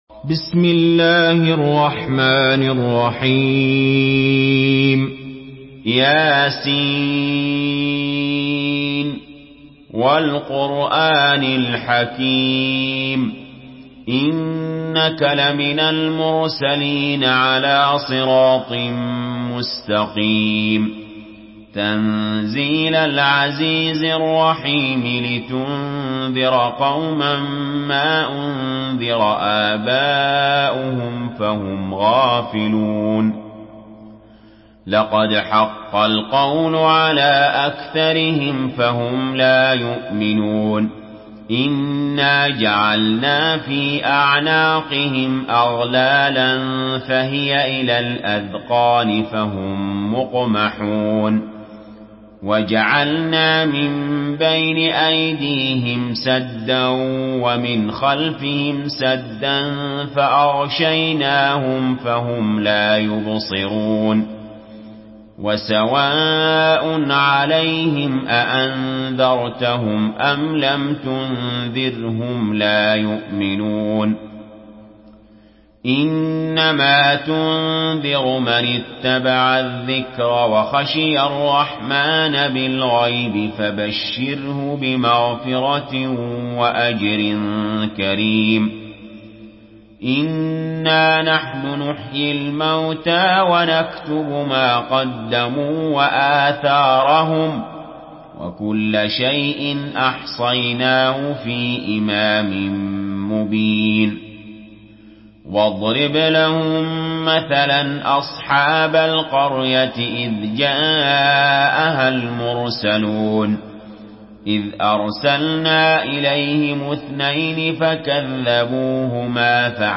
Surah Yasin MP3 by Ali Jaber in Hafs An Asim narration.
Murattal Hafs An Asim